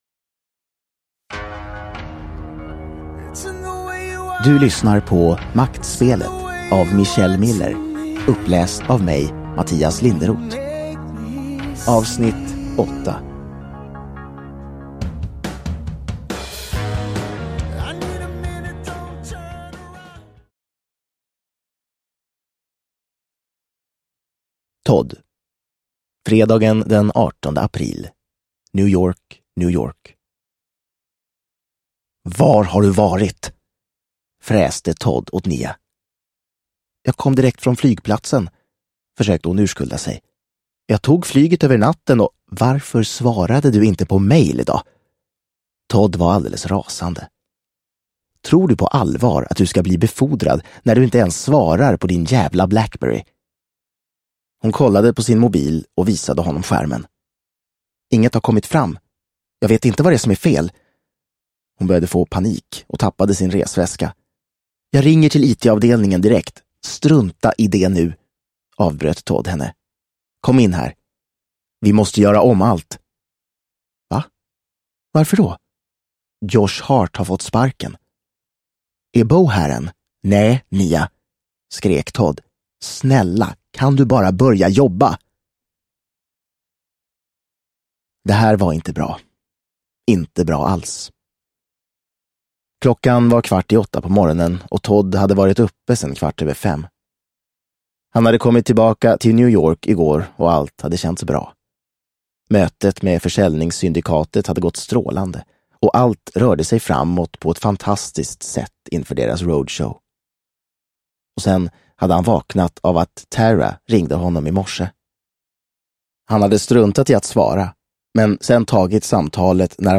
Maktspelet Del 8 – Ljudbok – Laddas ner